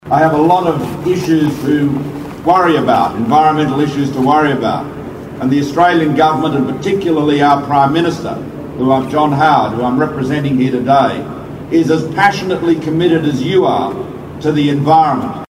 This morning Boy Scouts celebrated the centenary of scouting at the Opera House ... panorama.
Malcolm Turnbull, the Federal Minister for the Environment and Water was there -- in full election mode --